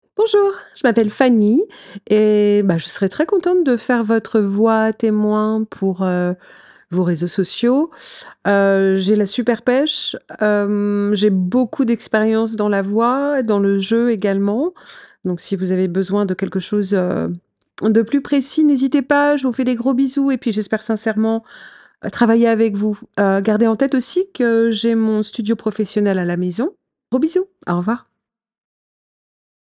Voix off
27 - 72 ans - Mezzo-soprano